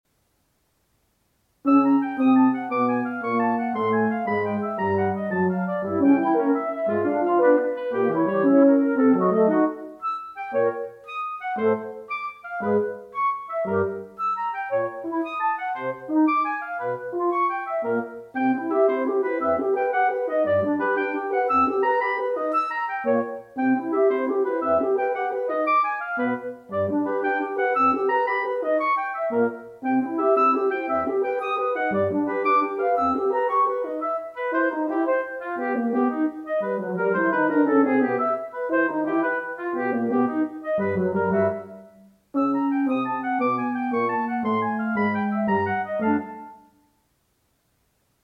Wind Quintet
Instrumentation: Flute, Oboe, Clarinet, Horn in F, Bassoon